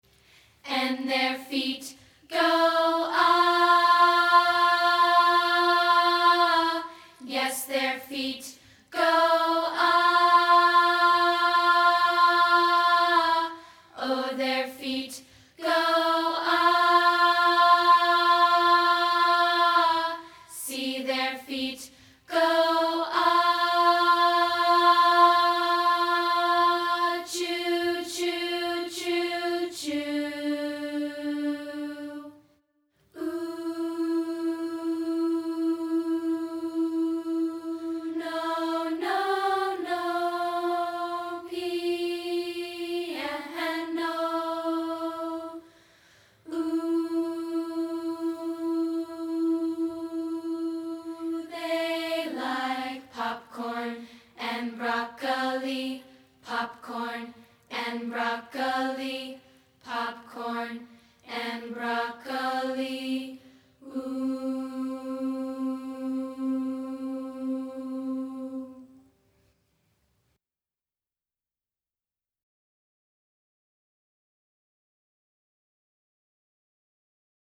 optional background vocals